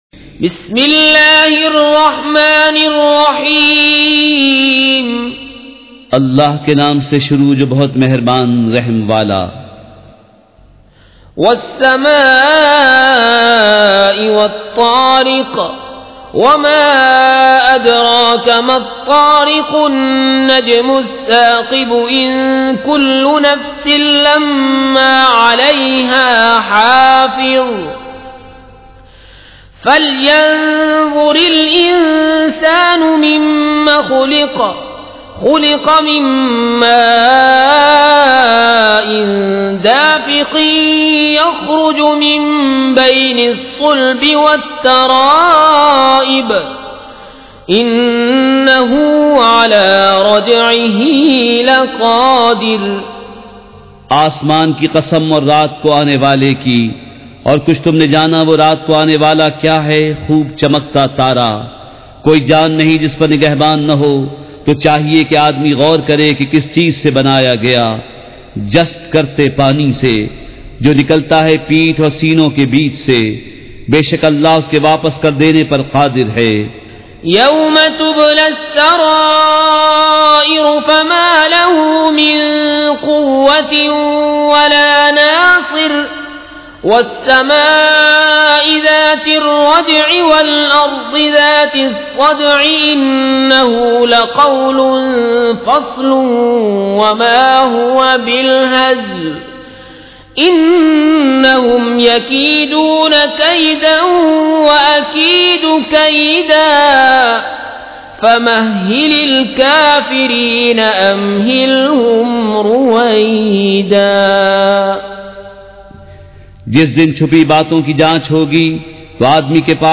سورۃ الطارق مع ترجمہ کنزالایمان ZiaeTaiba Audio میڈیا کی معلومات نام سورۃ الطارق مع ترجمہ کنزالایمان موضوع تلاوت آواز دیگر زبان عربی کل نتائج 1779 قسم آڈیو ڈاؤن لوڈ MP 3 ڈاؤن لوڈ MP 4 متعلقہ تجویزوآراء